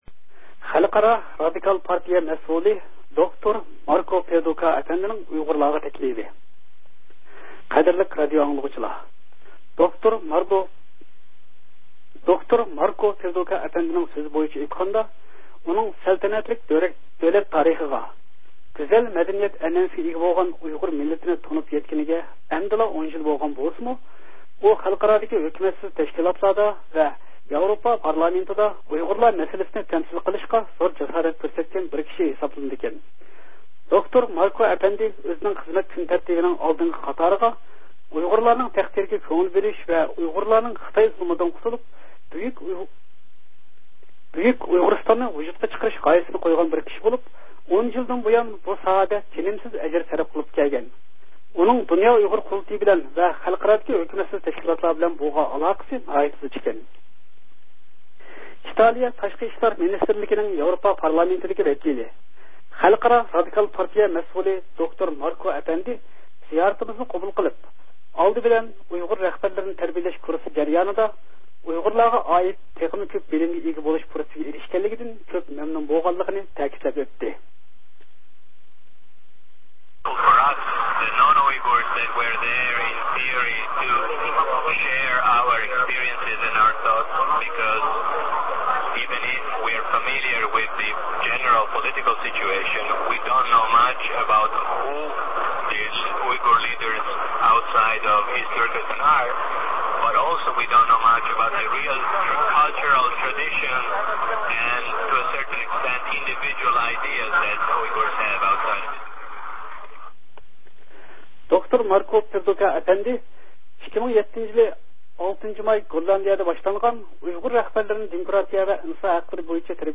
خەلقئارالىق رادىكال پارتىيىنىڭ مەسئۇلى ماركو پېردۇسا ئەپەندى بىلەن سۆھبەت